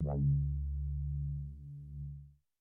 Korg Mono Poly Big Willy Bass " Korg Mono Poly Big Willy Bass F3（11 Big Willy Bass55127
标签： FSharp3 MIDI音符-55 Korg的 - 单 - 保利 合成 单注 多重采样
声道立体声